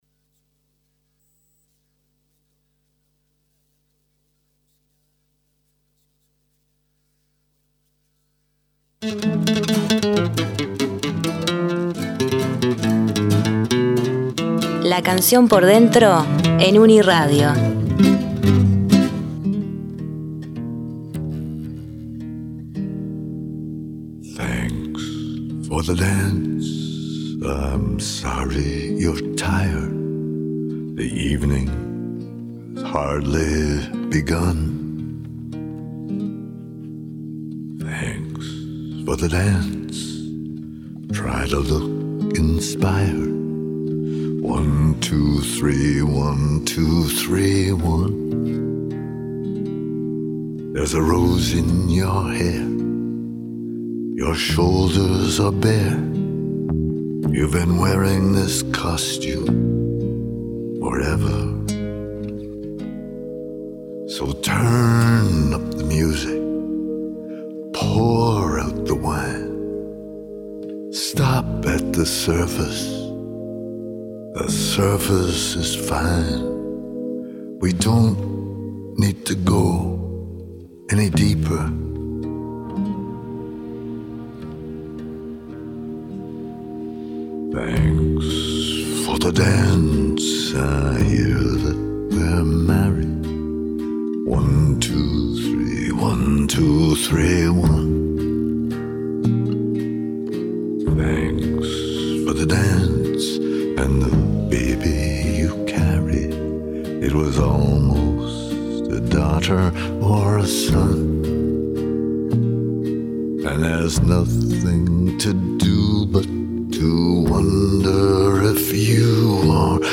Oímos las voces de la exquisita exponente del tango porteño